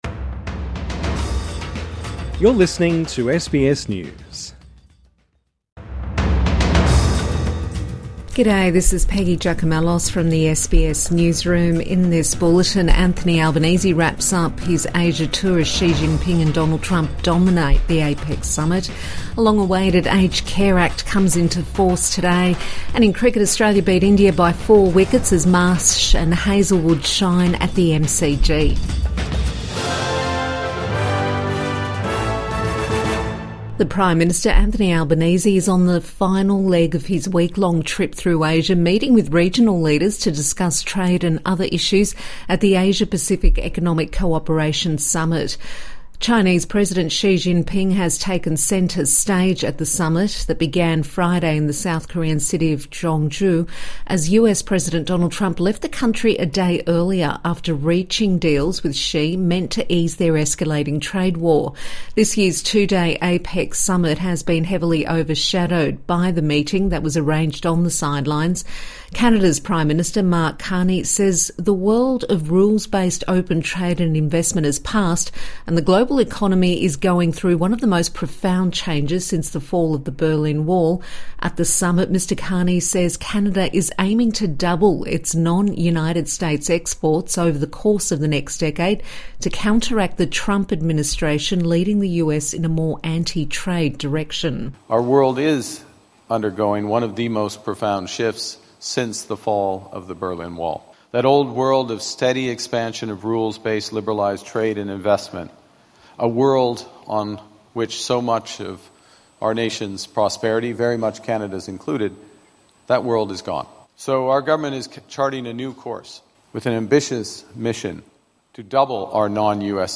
Morning News Bulletin 1 November 2025